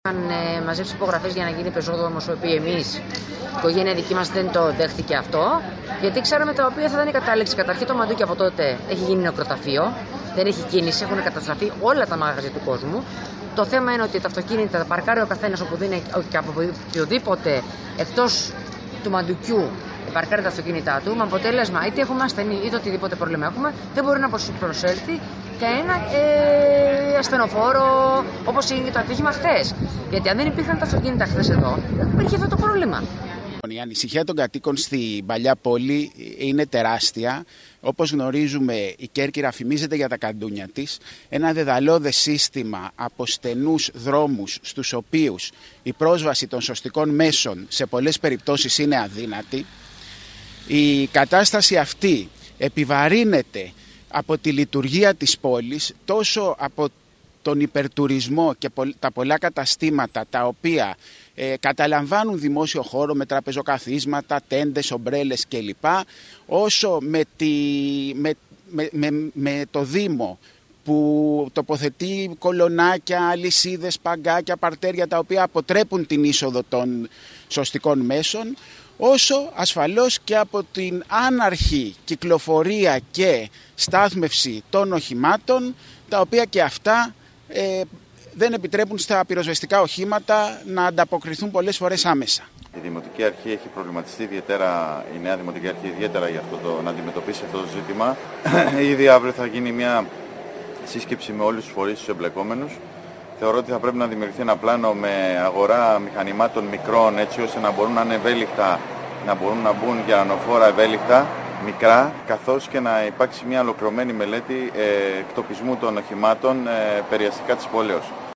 Τον προβληματισμό τους για τα ζητήματα που υπήρξαν κατά την πρόσβαση των πυροσβεστικών οχημάτων ανέπτυξαν στο μικρόφωνο της ΕΡΤ κάτοικοι του Μαντουκιού